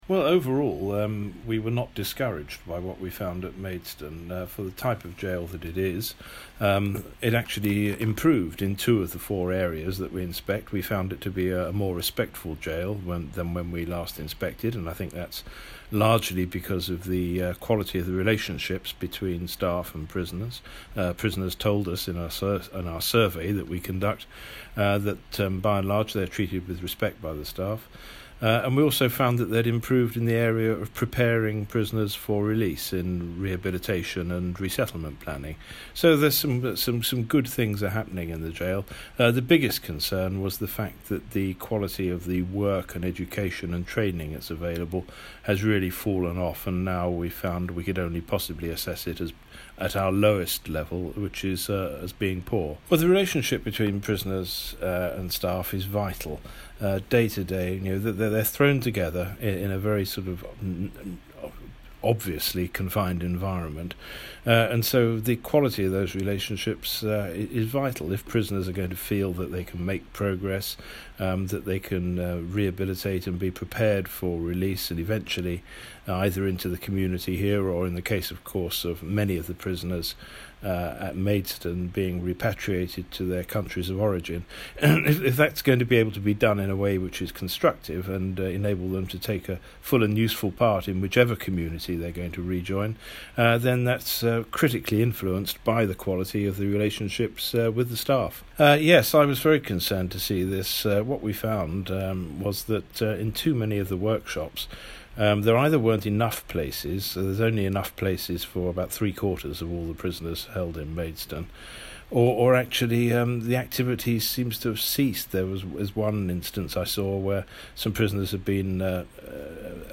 LISTEN: The Chief Inspector of Prisons, Peter Clarke, says the quality of work and education available at HMP Maidstone has 'fallen off' - 19/02/19